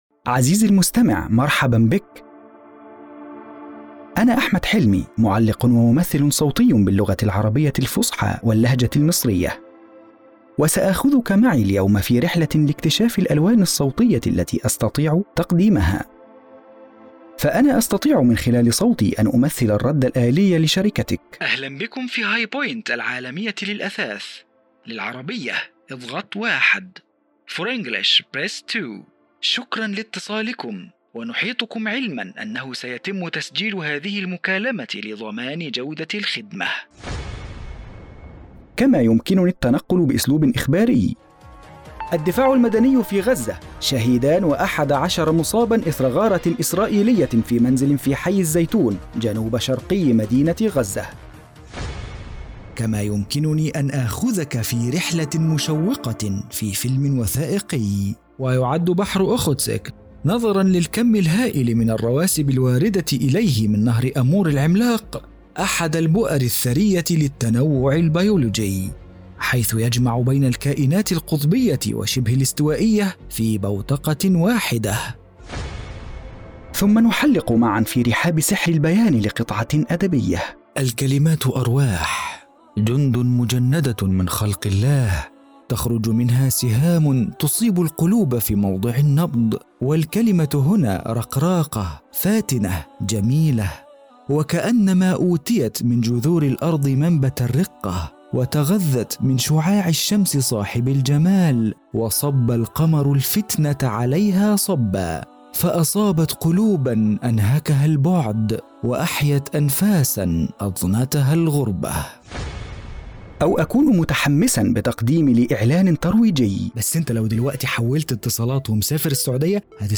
• ذكر
• العروض التشويقية (برومو)
• العربية الفصحى
• باريتون Baritone (متوسط العرض)
• في منتصف العمر ٣٥-٥٥